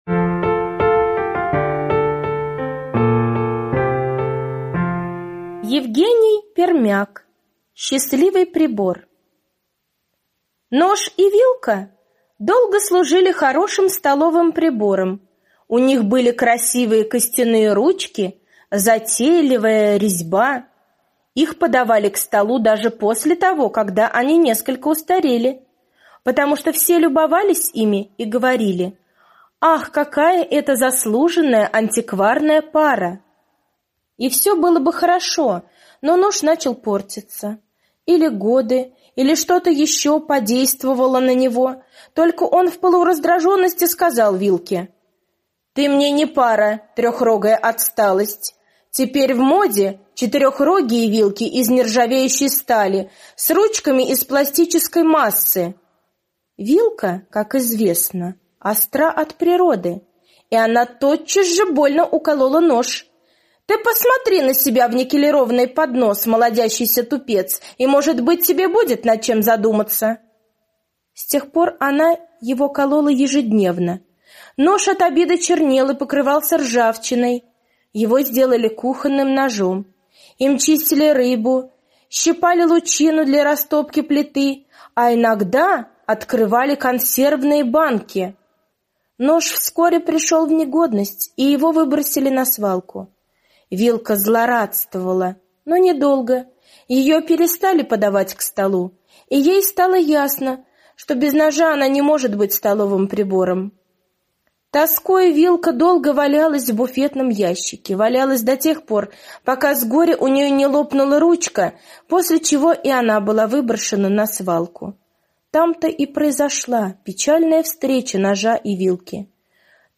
На данной странице вы можете слушать онлайн бесплатно и скачать аудиокнигу "Счастливый прибор" писателя Евгений Пермяк.